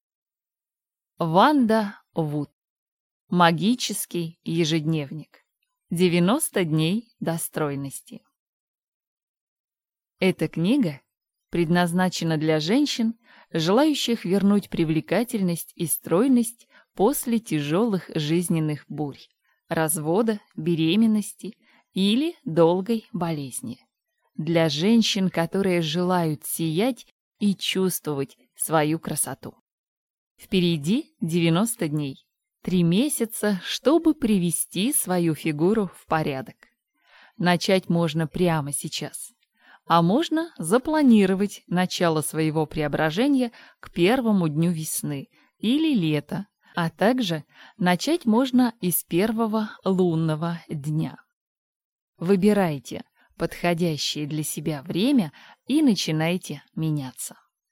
Аудиокнига Магический ежедневник. 90 дней до стройности | Библиотека аудиокниг